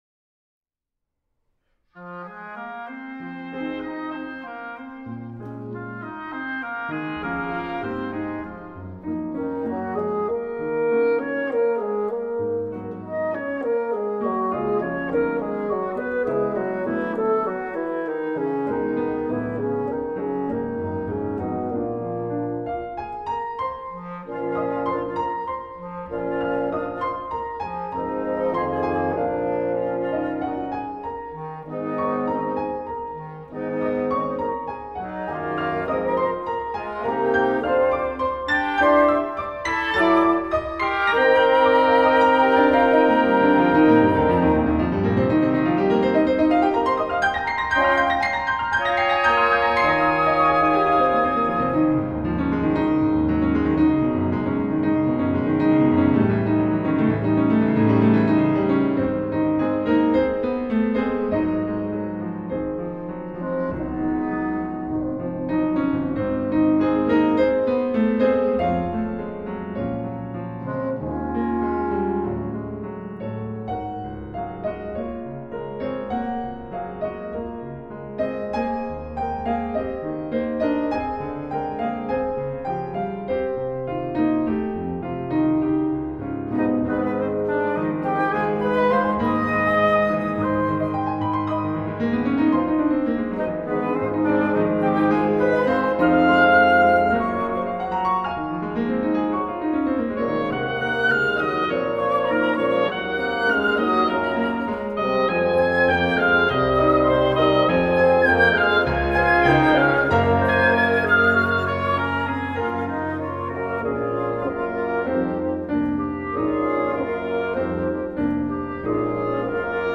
A three-movement sextet filled with verve and melodic charm.